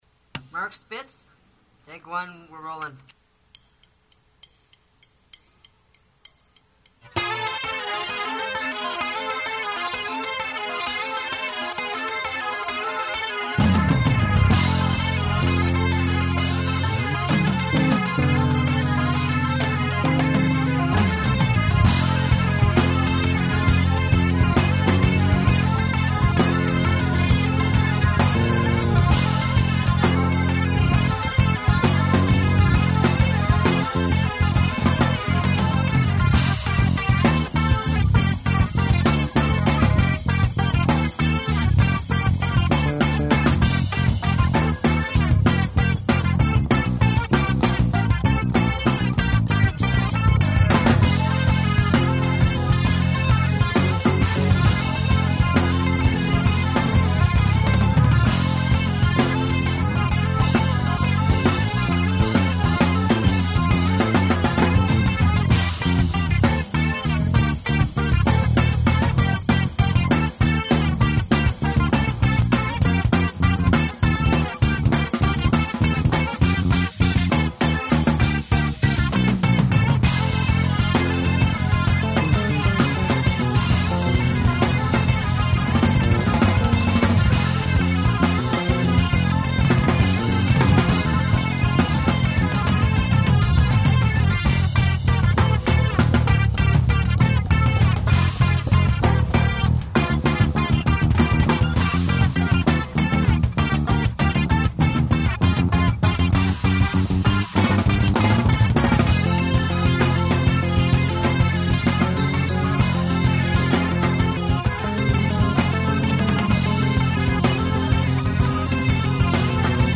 it's just the rhythm guitar, bass and drums